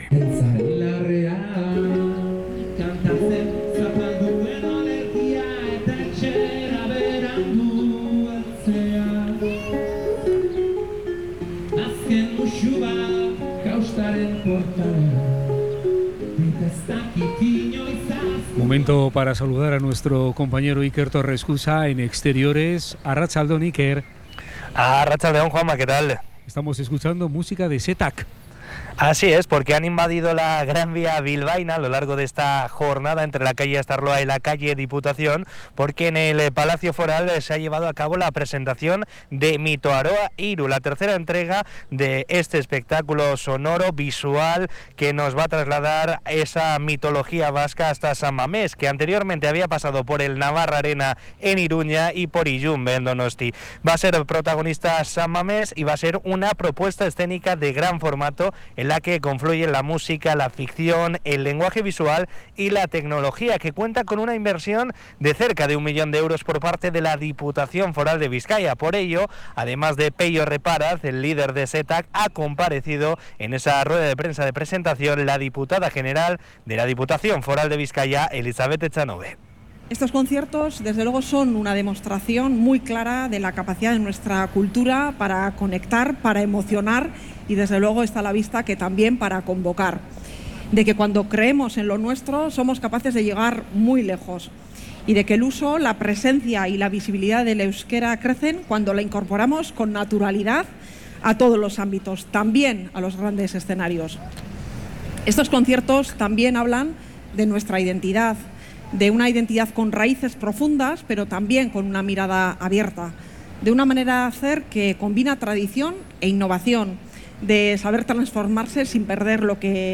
Durante la rueda de prensa, la Diputada General de Bizkaia, Elixabete Etxanobe, ha subrayado el inmenso valor de esta propuesta artística.